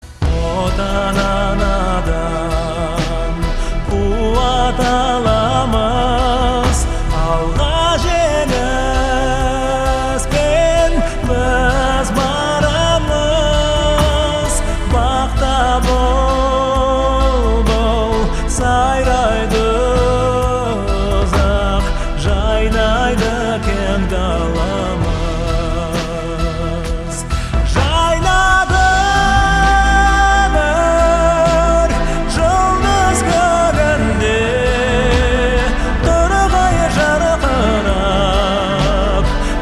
• Качество: 256, Stereo
душевные
спокойные
инструментальные
красивый мужской вокал
казахские